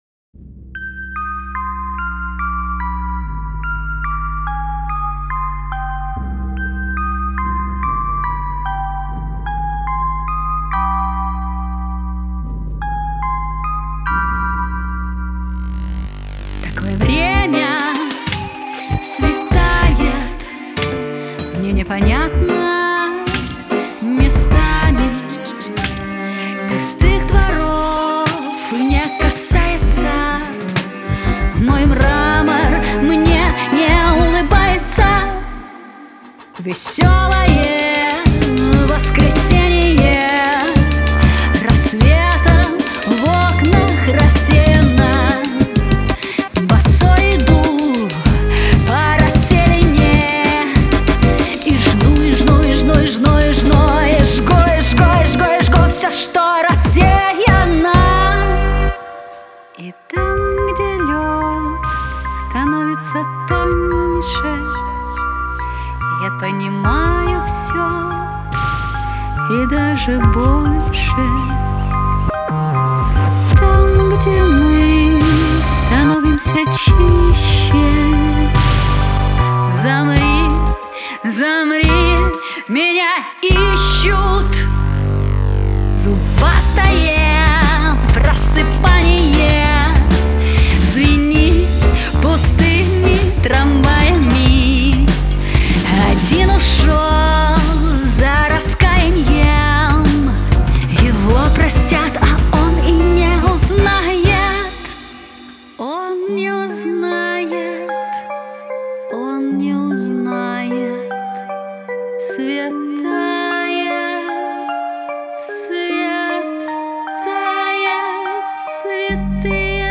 Музыка, вокал, текст - моя жена, аранж, продакшн - я. Ловлю помидоры)